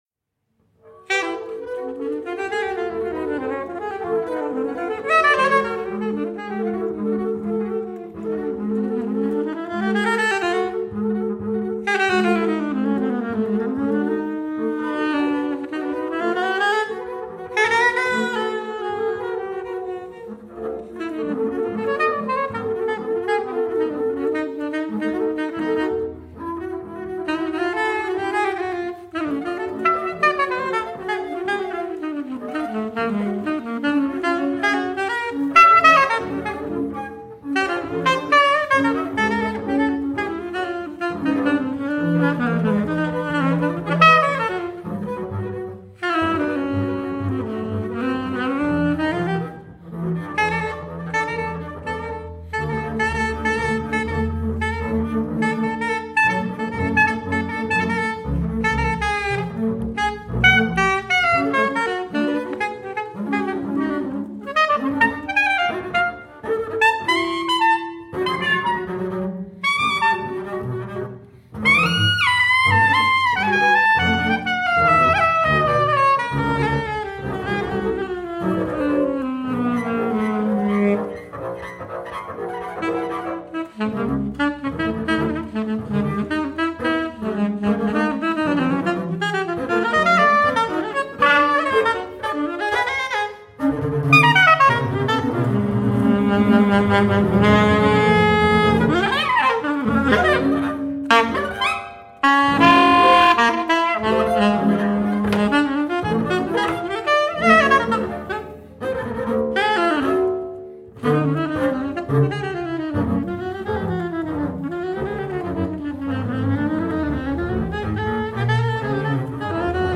double bass
sax